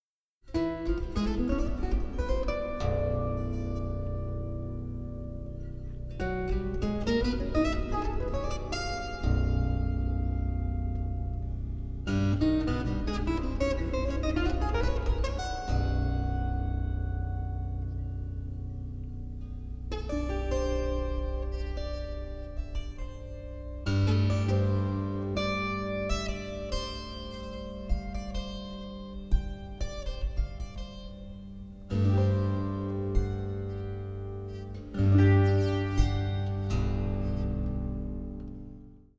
36-string Double Contraguitar, 30-string Contra-Alto guitar